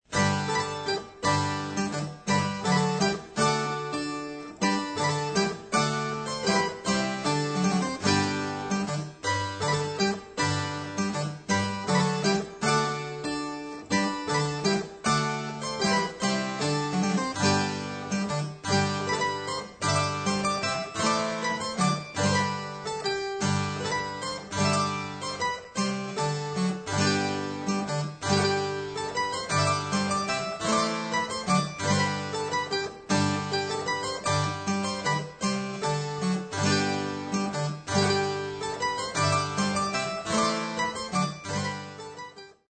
Le "Muselar" est un virginal qui a le clavier à droite, le pincement est donc proche du milieu des cordes, cela donne un son très rond dans les aigus, et carrément "ronflant" dans les bases.
Vous pouvez écouter le muselar avec l'enfant posé sur la mère dans un extrait de la Volta de W.Byrd en cliquant ici.